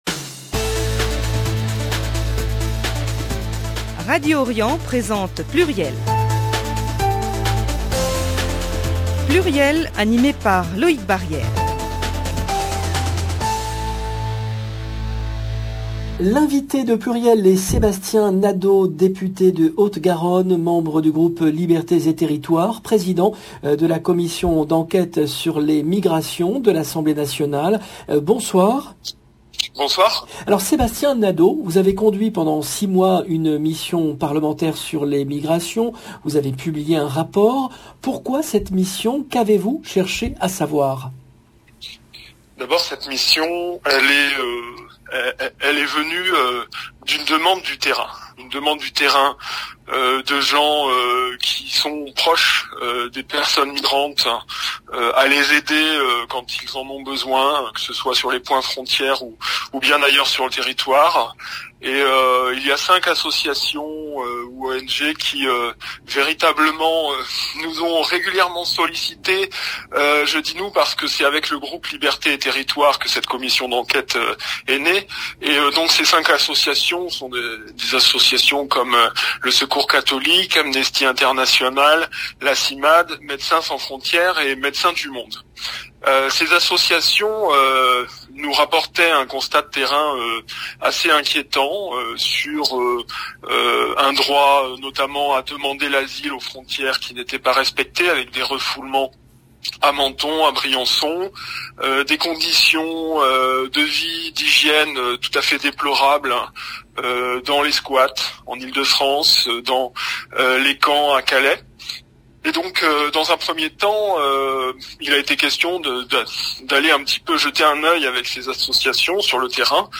PLURIEL, le rendez-vous politique du vendredi 19 novembre 2021